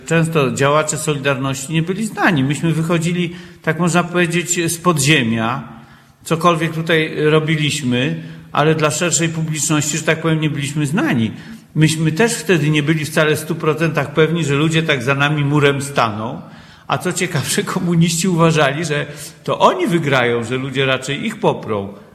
Ponieważ audycja prowadzona była na żywo z siedziby Muzeum Historycznego w Ełku, gdzie do 9 czerwca możecie zwiedzać wystawę „Wybory ’89 w Ełku”.